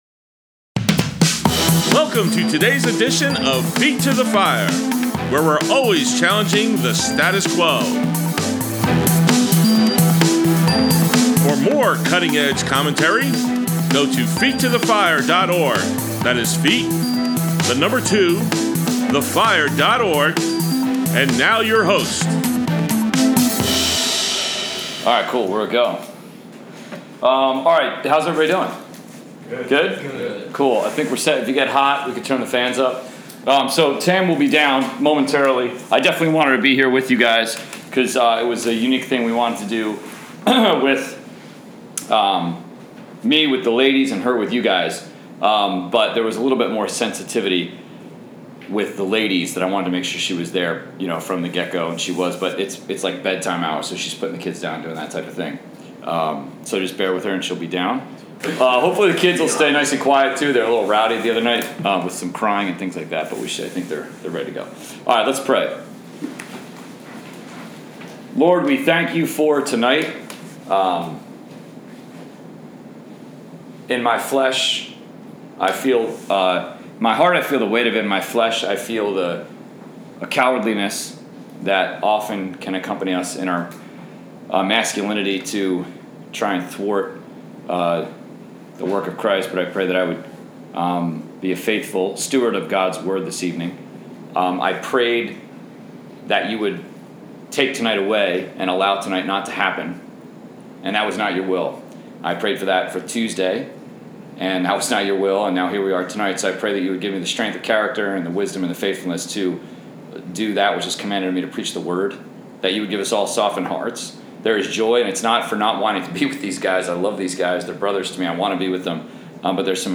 College/Career Group Men’s Study